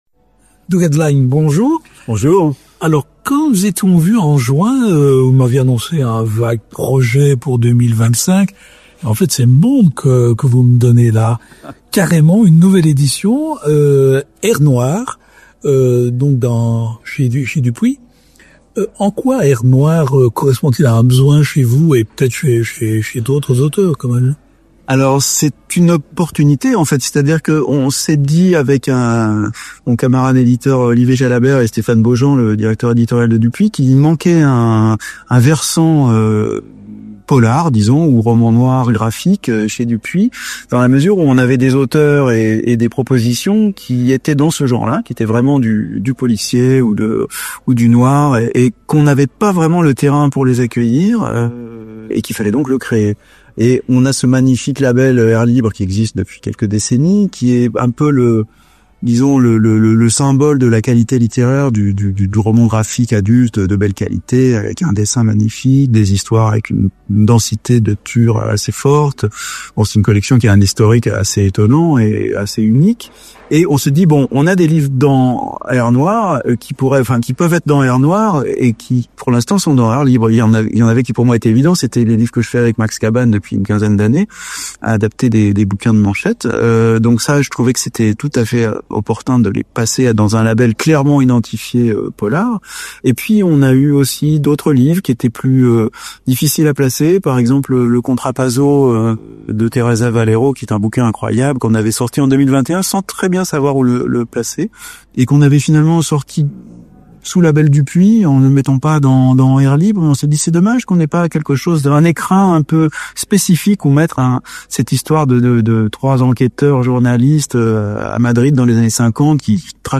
Rencontre. On y parle de Jason Statham, de Nada, de parodie, de Lee Marvin, de flashback, du Dortmunder de Westlake, de Chabrol, de Manchette (Jean-Patrick, le paternel!).